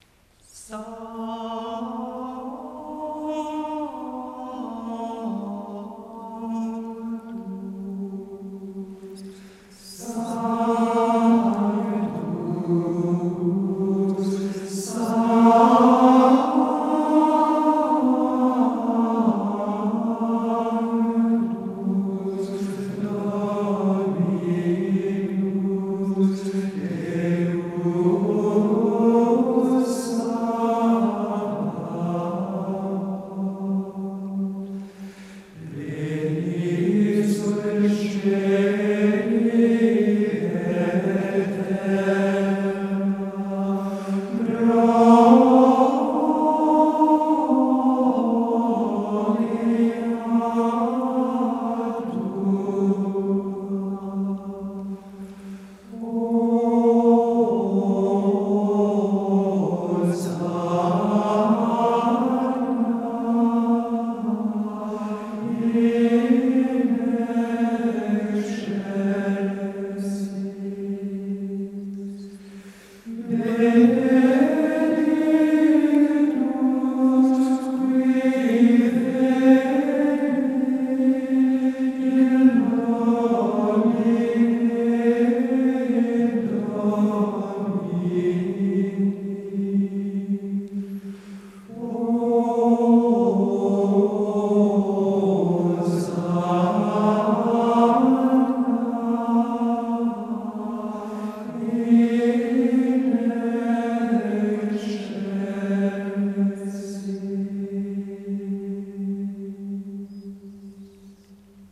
Il est très différent, emprunté qu’il est au 4ème mode, ce qui le range automatiquement dans les pièces mystiques du répertoire grégorien.
Les premier et troisième Sanctus, identiques tous les deux, jaillissent et encadrent un second Sanctus beaucoup plus modeste et intérieur.
Ce premier Sanctus est magnifique, d’emblée, très chaud, très contemplatif, très intérieur.
Un départ piano, une petite montée en crescendo sur l’accent et une retombée très douce vers la cadence.
Au total, un incomparable Sanctus, plein de majesté, de noblesse, de douceur délicieuse, de paix.